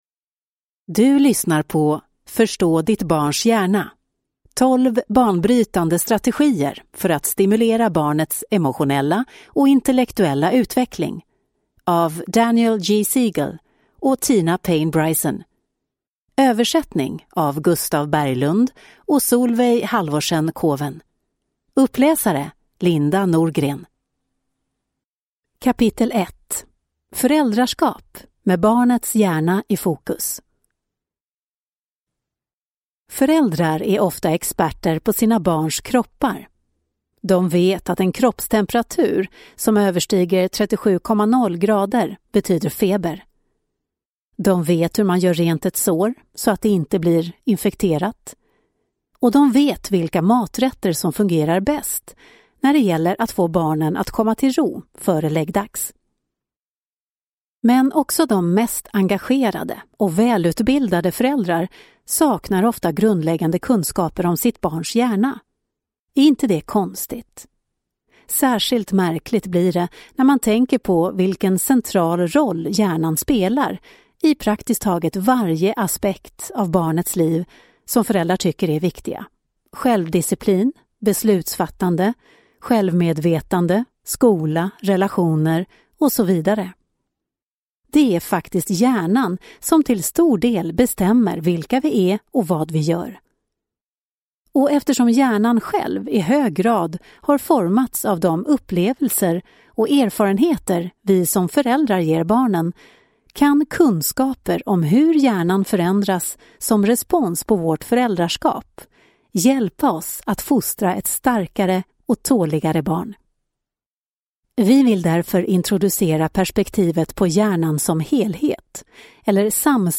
Ljudbok 185 kr